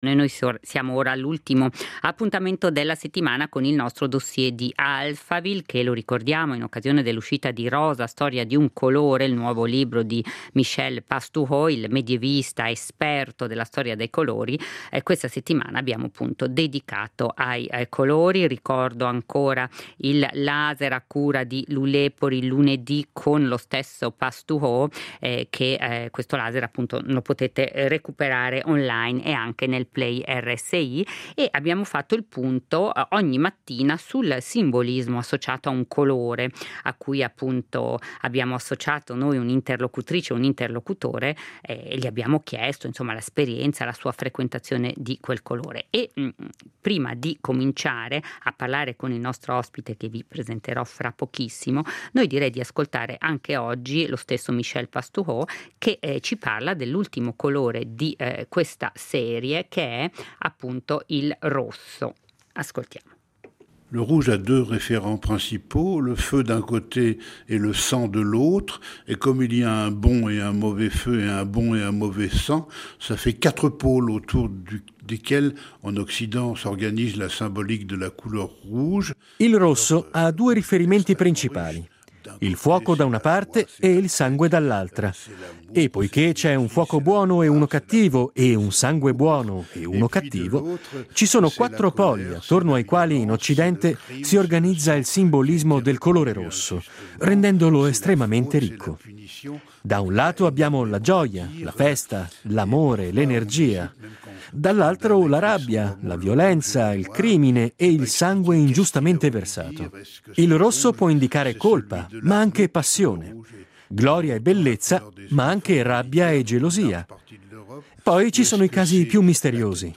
Il Dossier questa settimana prende le mosse da una lunga intervista a Michel Pastoureau , autore del saggio “ Rosa. Storia di un colore”.